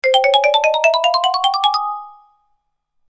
Cartoon Ladder Climb High-Pitch Marimba Sound Effect
Experience an ascending marimba melody that illustrates footsteps or stair climbing with a playful rhythm.
Genres: Sound Effects
Cartoon-ladder-climb-high-pitch-marimba-sound-effect.mp3